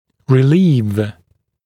[rɪ’liːv][ри’ли:в]облегчать, уменьшать, ослаблять